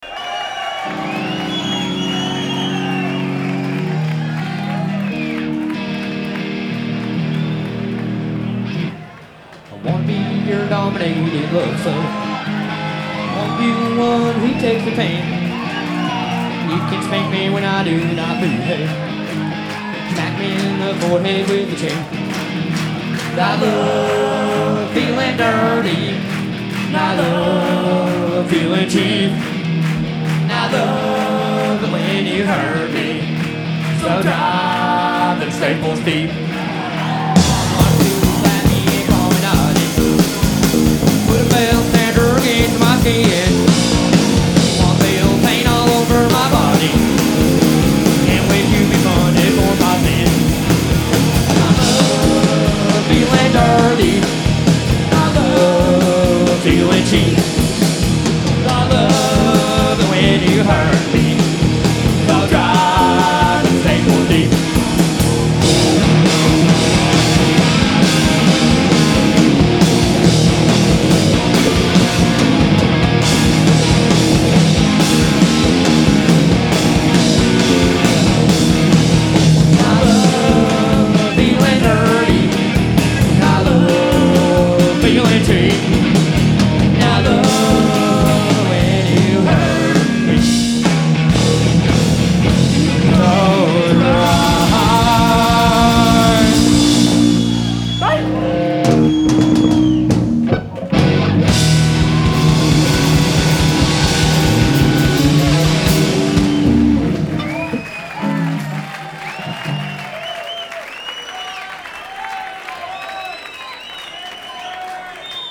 Genre : Alternative & Indie
Live at Garatge Club, Barcelona 1994